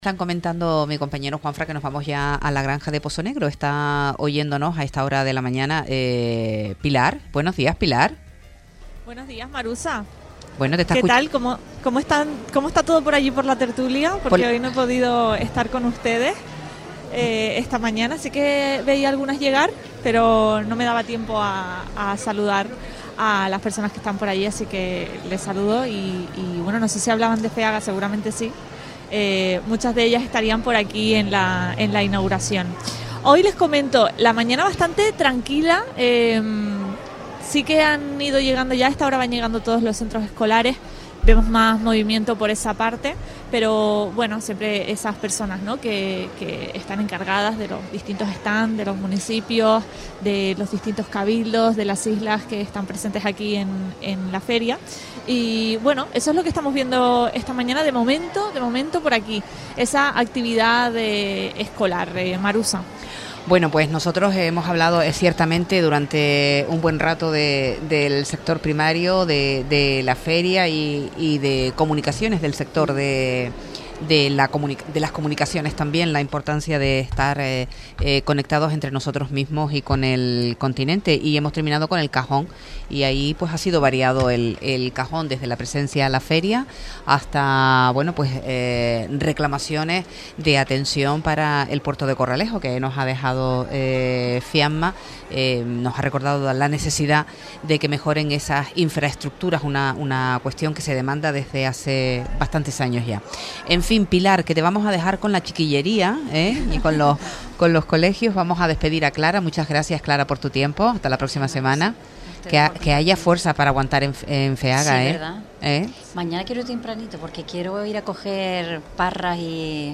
En el marco de FEAGA 2026, continuamos nuestra cobertura durante la segunda jornada de la feria con una intervención centrada en el contacto directo con los asistentes. Recorremos diversos stands para conocer de primera mano las propuestas que se presentan este año, vinculadas al sector primario y el producto local.
Entrevistas